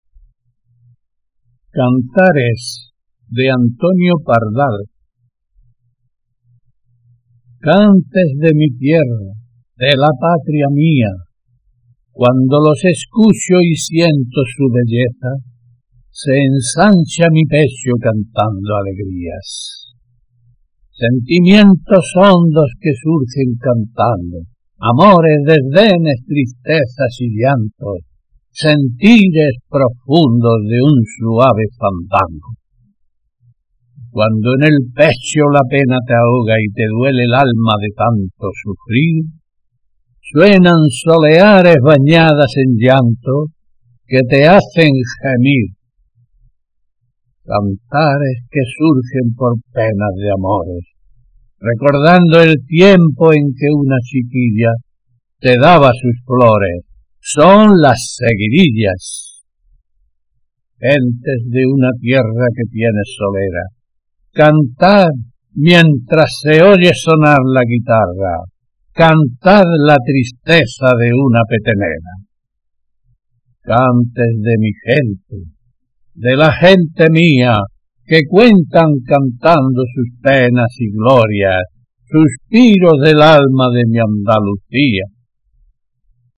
Y digo nos lo está haciendo, porque después de esas dos primeras declamaciones me ha mandado otras tres más, muy buenas también.